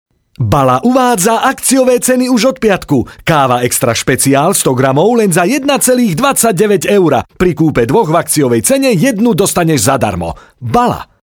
slowakischer Sprecher
Sprechprobe: Industrie (Muttersprache):
slovakian voice over talent